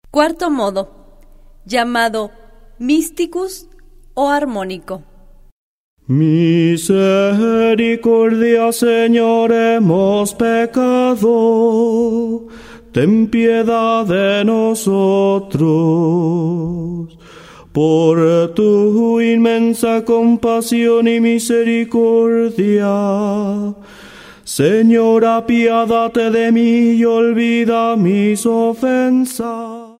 05 Cuarto modo gregoriano.